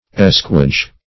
Escuage - definition of Escuage - synonyms, pronunciation, spelling from Free Dictionary
Escuage \Es"cu*age\ (?; 48), n. [OF. escuage, F. ['e]cuage, from